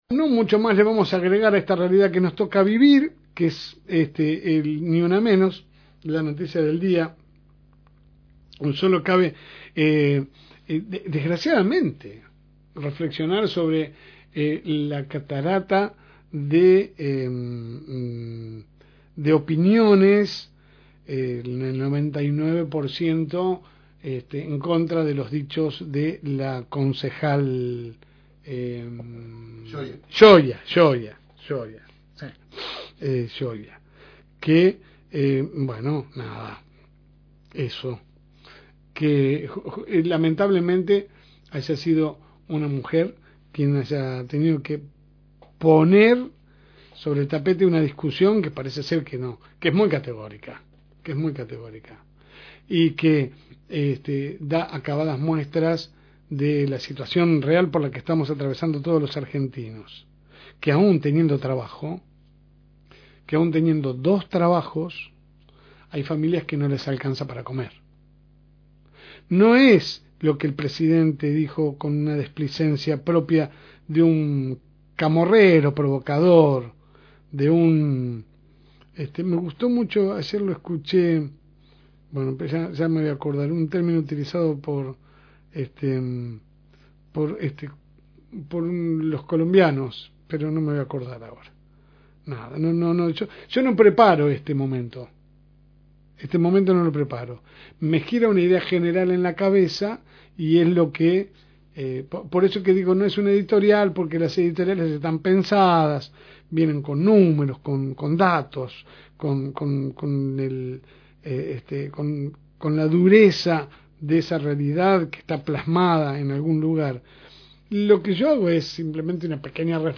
AUDIO – Editorial – FM Reencuentro